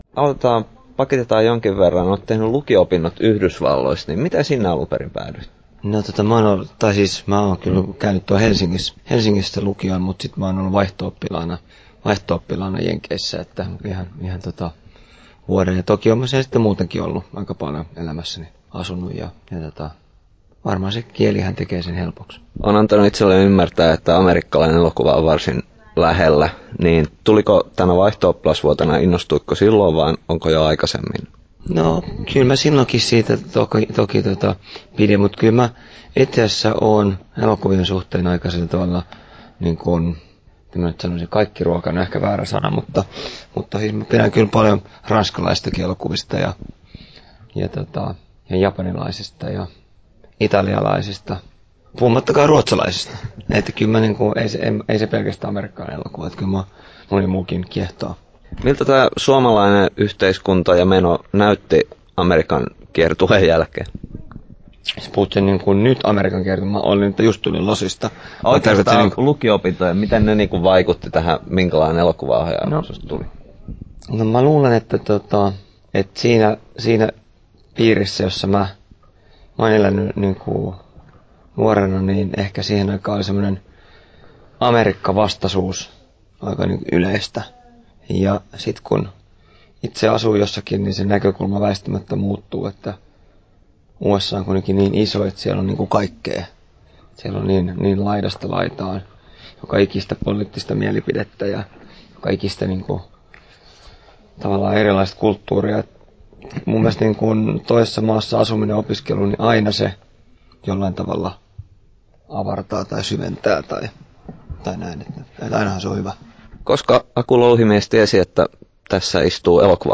Aku Louhimiehen haastattelu Kesto: 12'44" Tallennettu: 13.2.2013, Turku Toimittaja